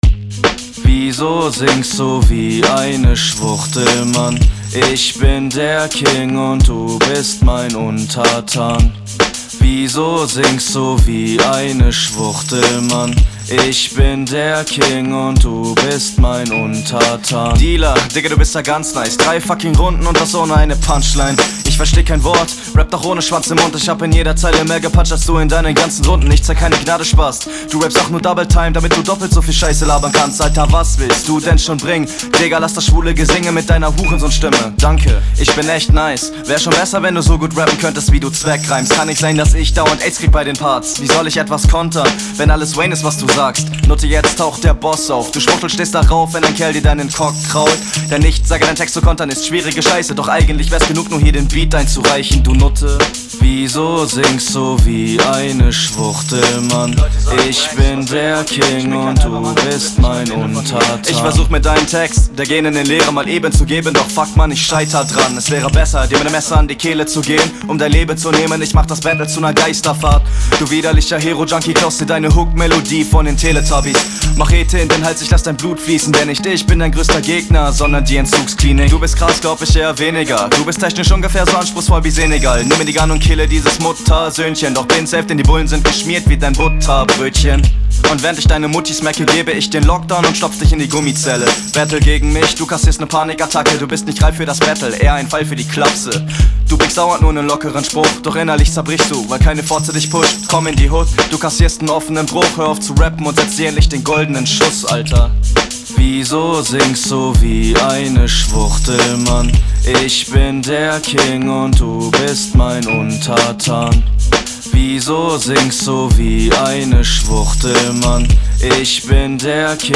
"Hook" ist leicht schief und auch sehr oberflächlich, aber irgendwie trotzdem dope.